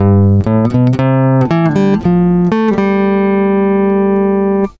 VINGER OEFENING *
* Bovenstaande vingeroefening is op basis van La Bamba